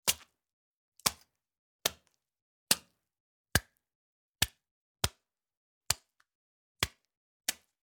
На этой странице собраны натуральные аудиоэффекты, связанные с огурцами: от хруста свежего овоща до звуков его выращивания.
звук удара кулаком по огурцу